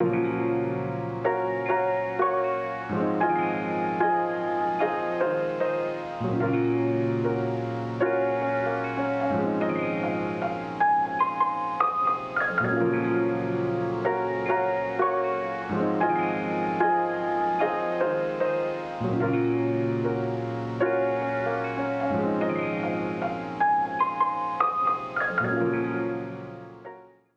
jazz keys 5.wav